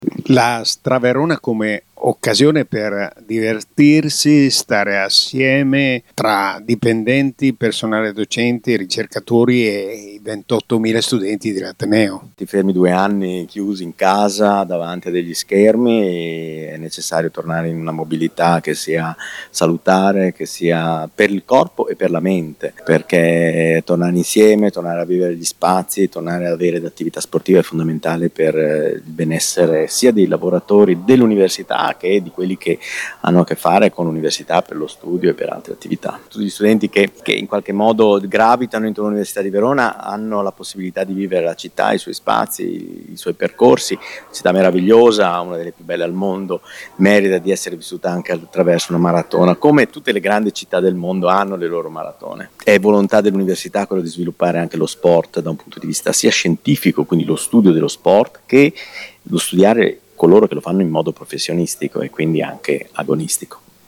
Le interviste del nostro corrispondente